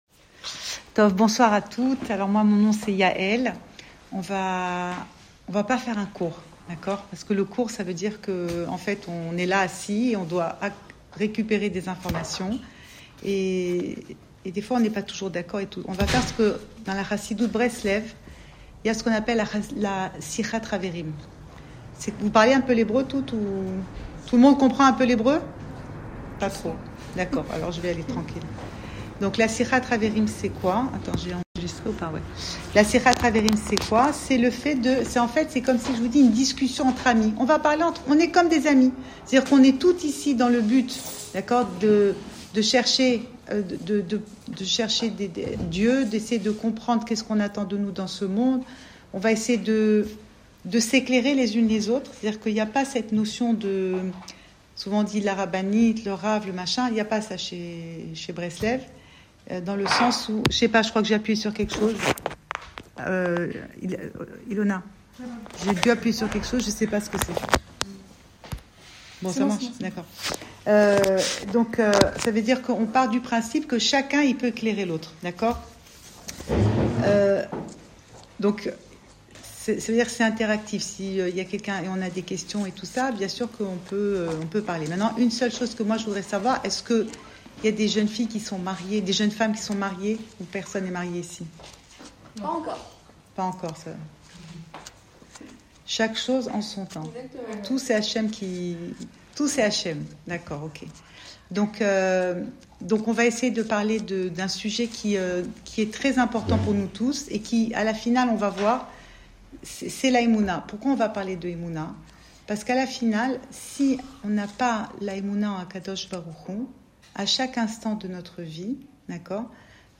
» Cours audio Emouna Le coin des femmes Le fil de l'info Pensée Breslev - 26 novembre 2025 27 novembre 2025 « Et peut être que… » Enregistré à Tel Aviv